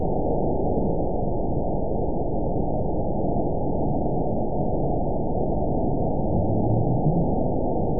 event 922707 date 03/15/25 time 16:32:39 GMT (1 month, 2 weeks ago) score 8.97 location TSS-AB02 detected by nrw target species NRW annotations +NRW Spectrogram: Frequency (kHz) vs. Time (s) audio not available .wav